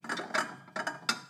Babushka / audio / sfx / Kitchen / SFX_Plates_03.wav
SFX_Plates_03.wav